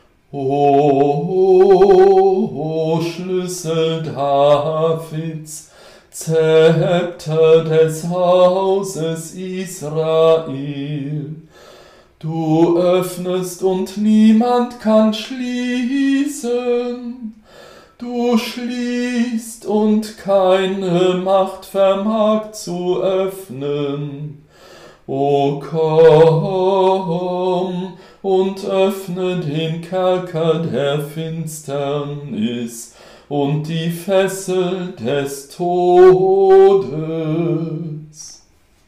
Antiphon